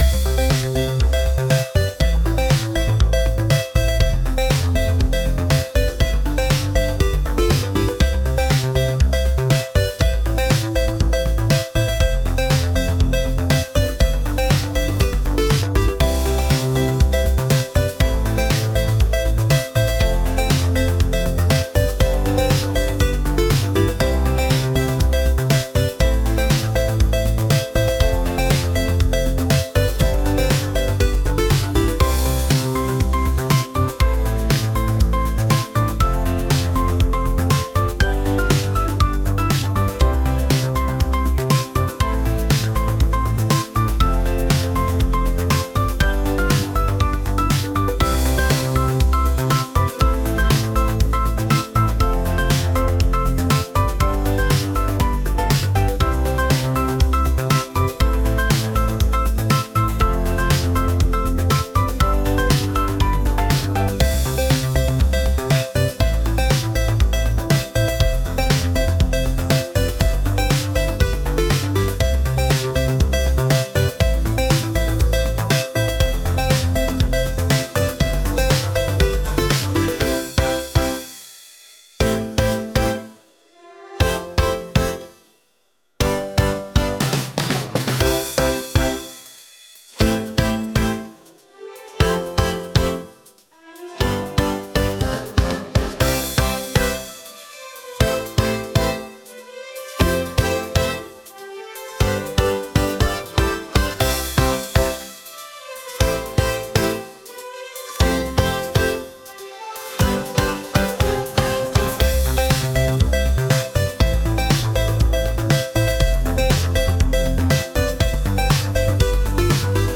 Retro Game Track